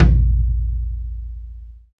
Index of /90_sSampleCDs/Sound & Vision - Gigapack I CD 1 (Roland)/KIT_REAL m 1-16/KIT_Real-Kit m 3
TOM TOM120.wav